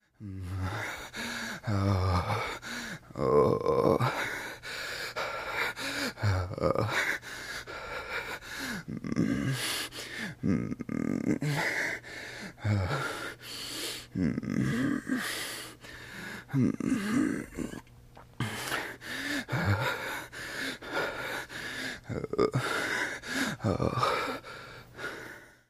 HeavyMaleBreathing AZ175703
Heavy Male Breathing, W Wheezes And Moans. 3